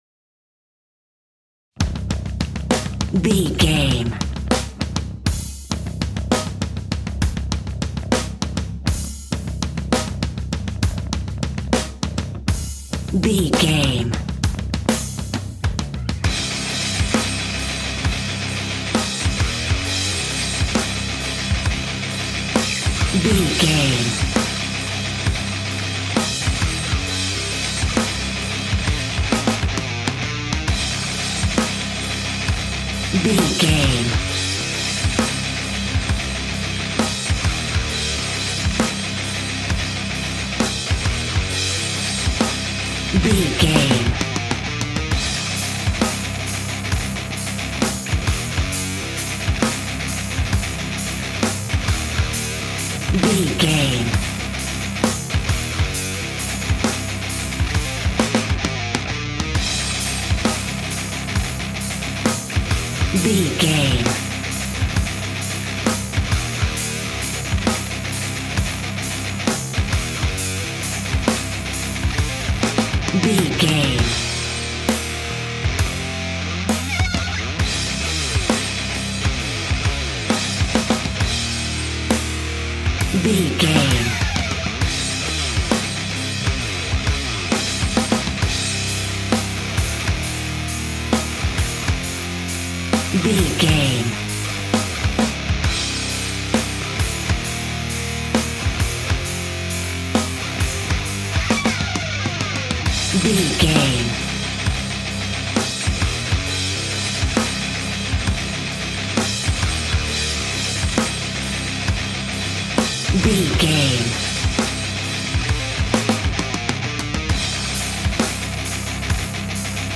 Epic / Action
Fast paced
Aeolian/Minor
hard rock
heavy metal
dirty rock
scary rock
rock instrumentals
Heavy Metal Guitars
Metal Drums
Heavy Bass Guitars